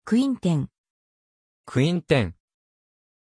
Pronunciation of Quinton
pronunciation-quinton-ja.mp3